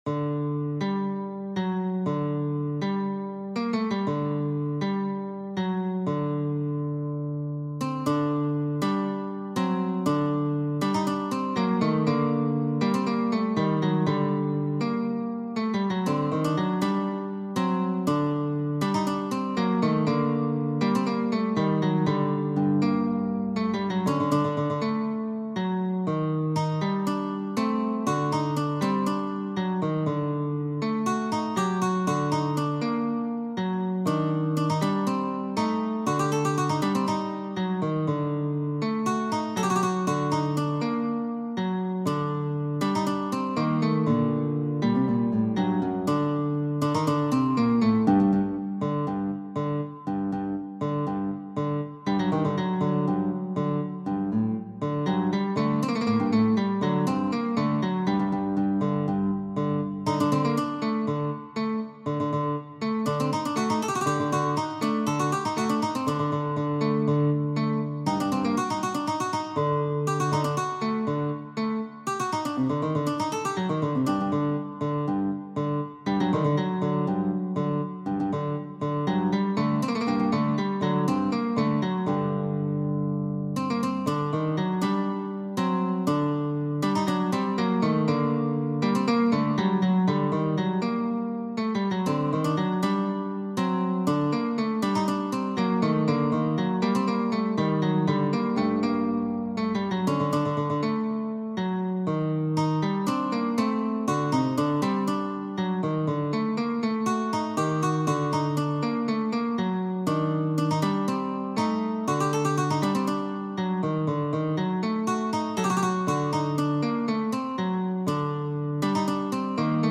Duet for Two Iraqi Ouds
Two Ouds 2:15 Arabic